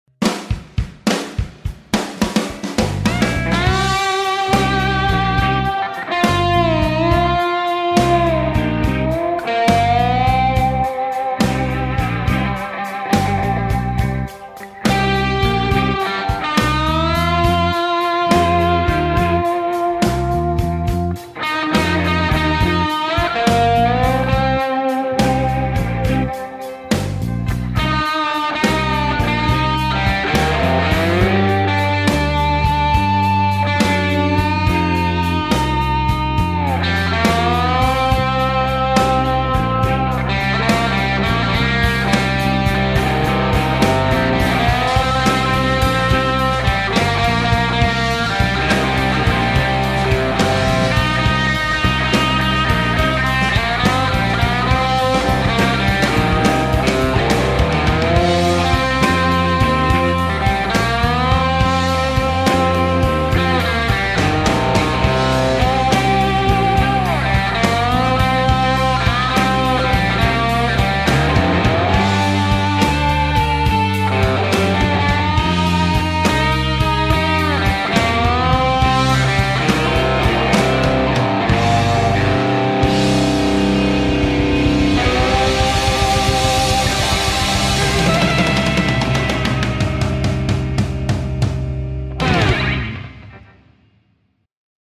- kun osallistut, soita roots-soolo annetun taustan päälle ja pistä linkki tähän threadiin
Hyvin muriseva oikein roots-henkinen slidesoundi. Tunnelmallista bluesmeininkiä ja sopivasti lisää rypistystä tokaan kieppiin.
Mukavan karhee slidesoundi.
hyvin slide soi, saundi hyvä.
Soundissa magiaa